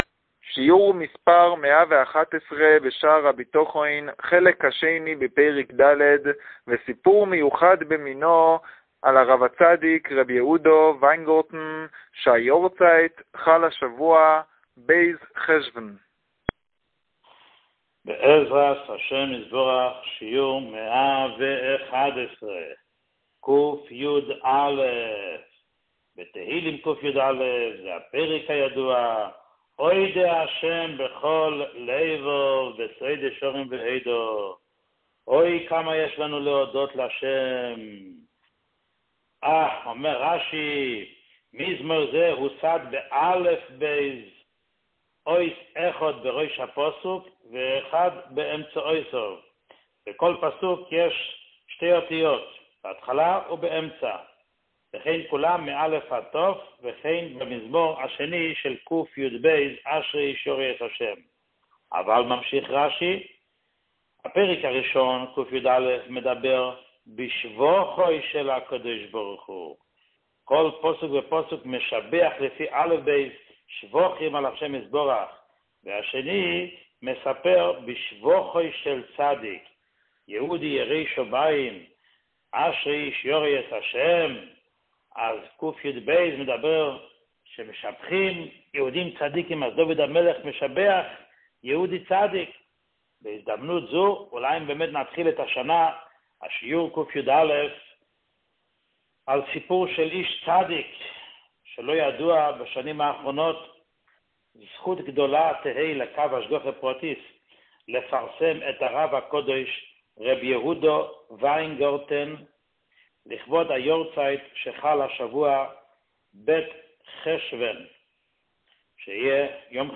שיעור 111